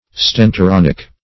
Stentoronic \Sten`to*ron"ic\, a.
stentoronic.mp3